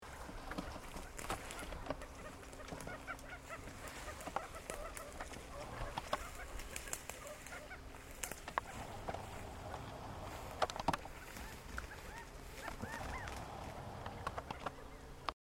Codorniz Californiana (Callipepla californica)
Fase de la vida: Adulto
Localidad o área protegida: Valle Inferior del Río Chubut (VIRCH)
Certeza: Observada, Vocalización Grabada
Codorniz-de-California.mp3